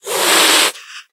snapshot / assets / minecraft / sounds / mob / cat / hiss2.ogg
hiss2.ogg